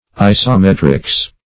isometrics \i`so*met"rics\, n.